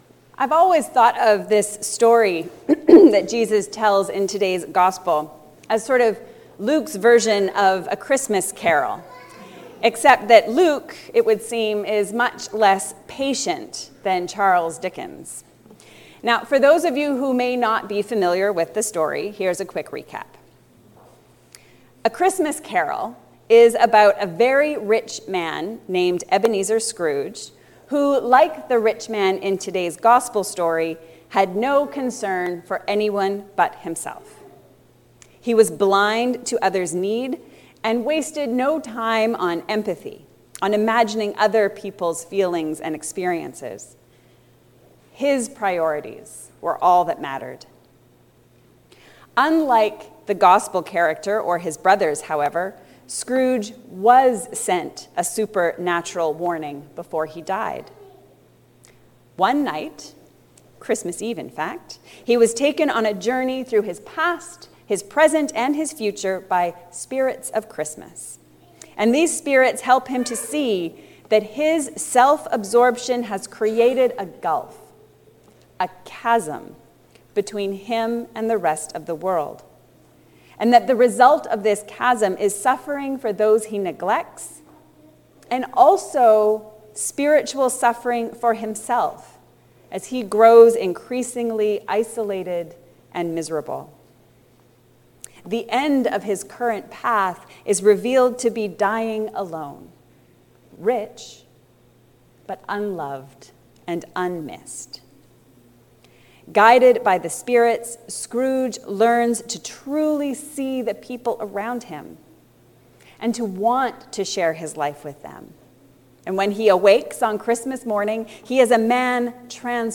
What we do matters. A sermon on Luke 16:19-31